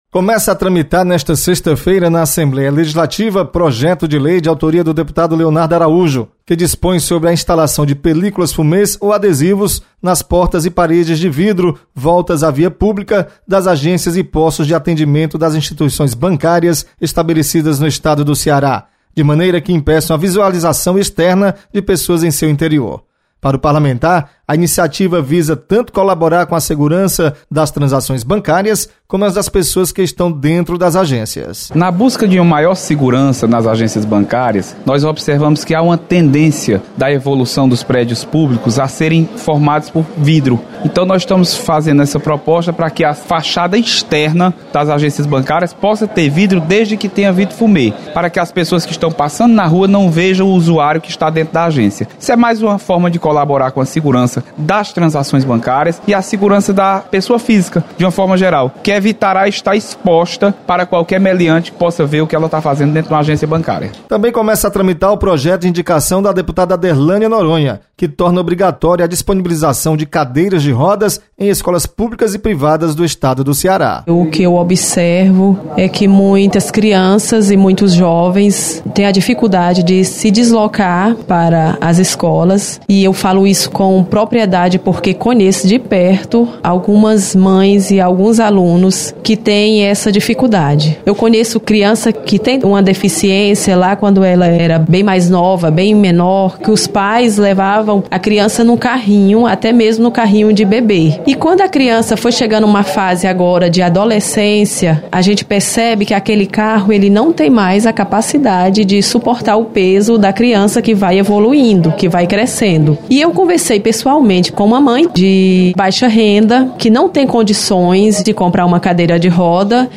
Você está aqui: Início Comunicação Rádio FM Assembleia Notícias Projeto